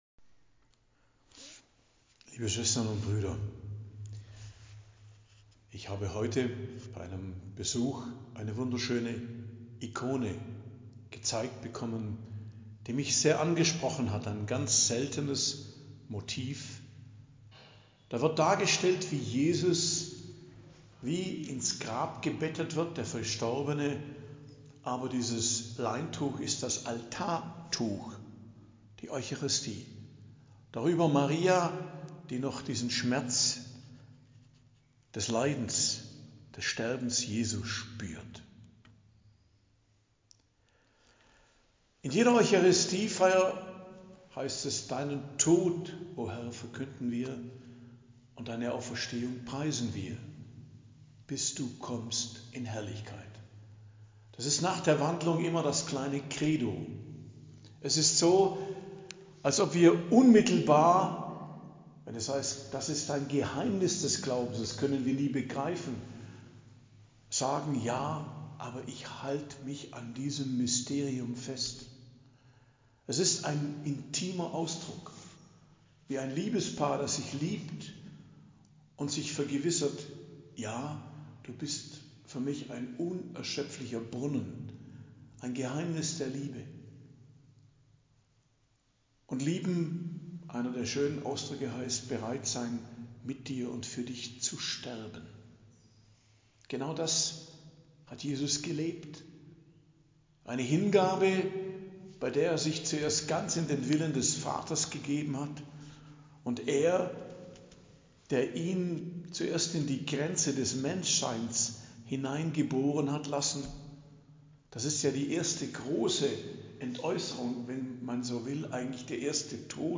Predigt am Dienstag der 2. Osterwoche, 29.04.2025